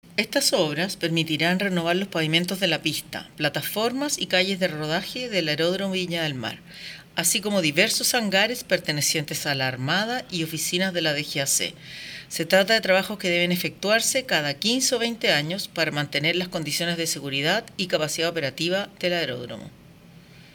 Claudia-Silva-Directora-Nacional-de-Aeropuertos-del-MOP.mp3